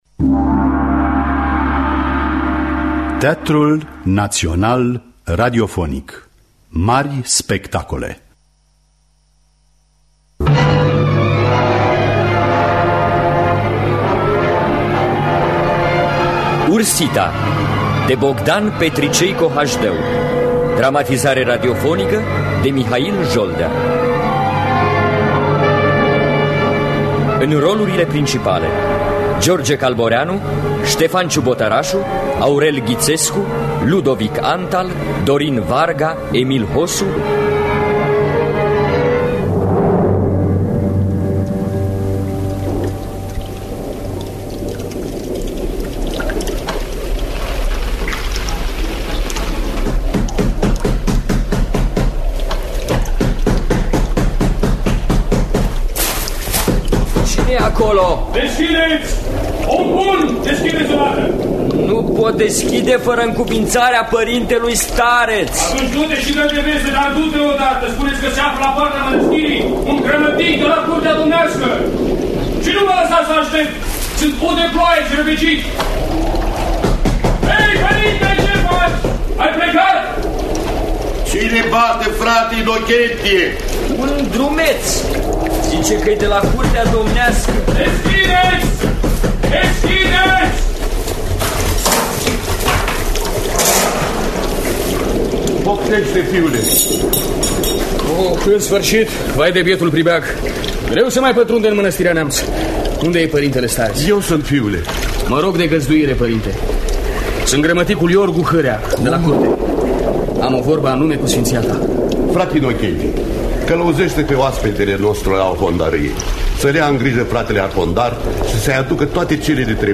Dramatizarea şi adaptarea radiofonică